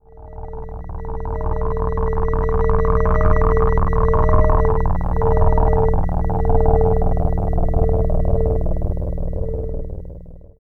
56.1 SFX.wav